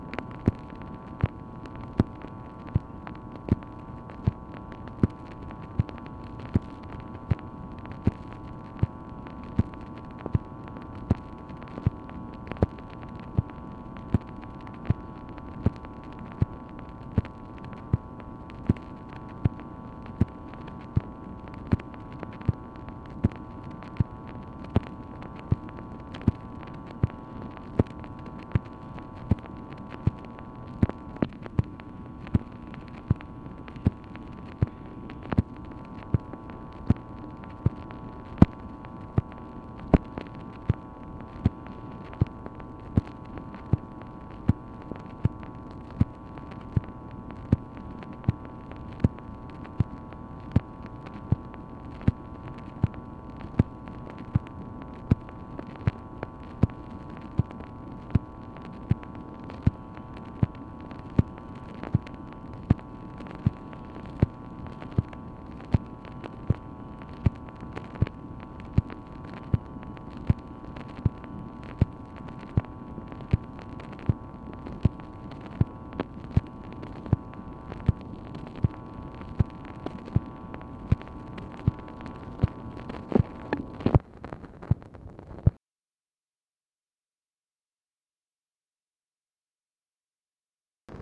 MACHINE NOISE, December 1, 1965